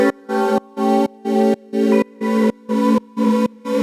Index of /musicradar/sidechained-samples/125bpm
GnS_Pad-MiscB1:4_125-A.wav